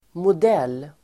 Uttal: [mod'el:]